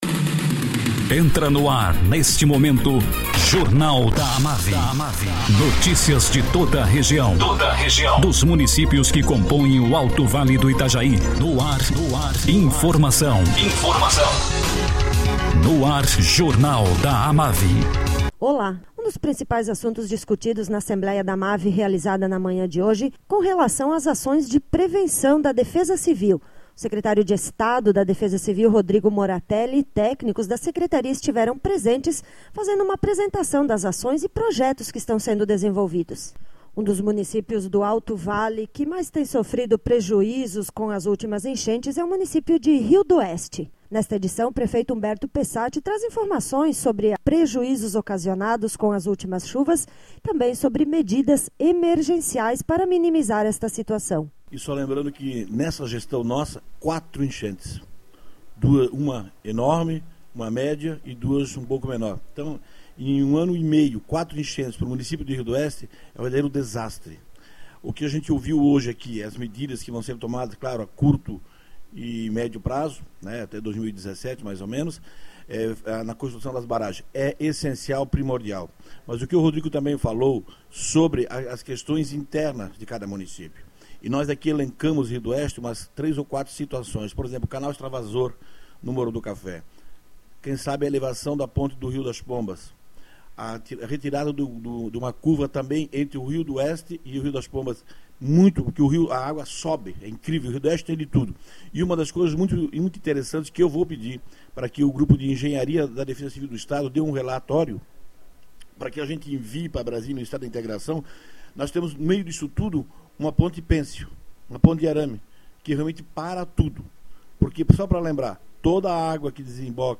Prefeito de Rio do Oeste, Humberto Pessatti, fala sobre as enchentes ocasionadas no município nos últimos anos e a necessidade urgente de medidas preventivas.